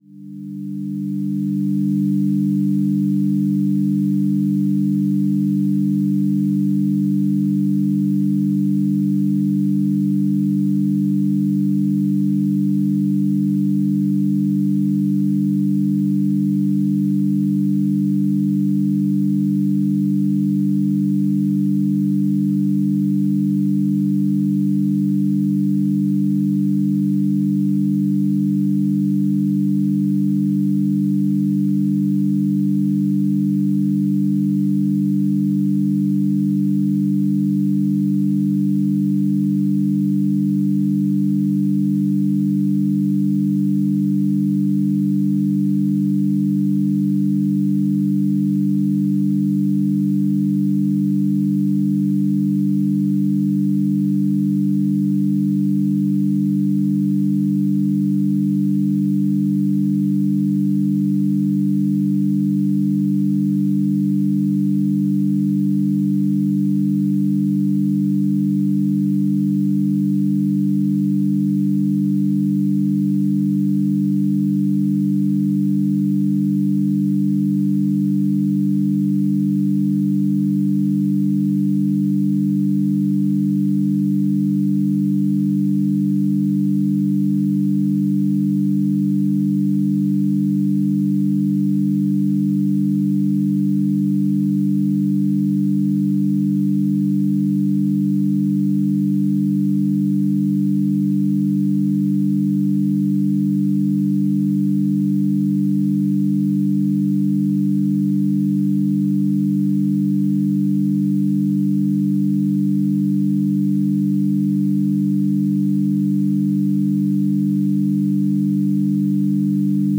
Simple ambient loops for testing:
**⚠  NOTE:** Music/SFX are PLACEHOLDERS (simple tones)
night_theme.wav